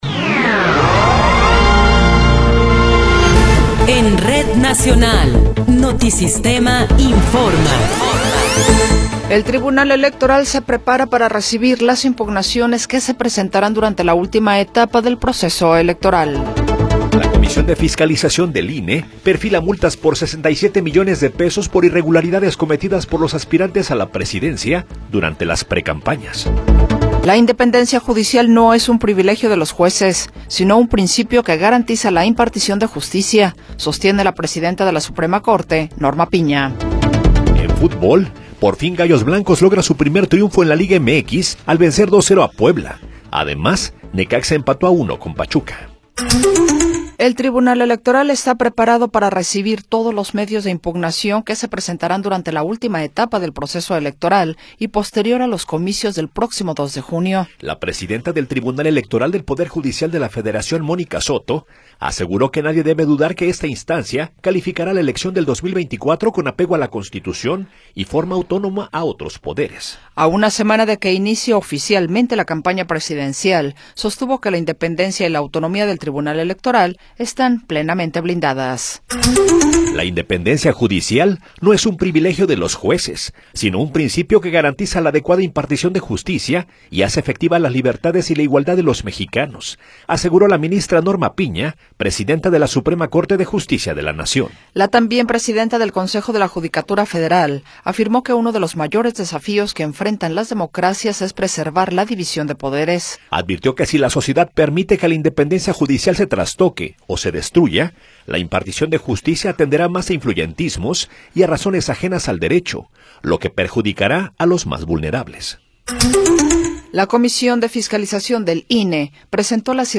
Noticiero 8 hrs. – 24 de Febrero de 2024
Resumen informativo Notisistema, la mejor y más completa información cada hora en la hora.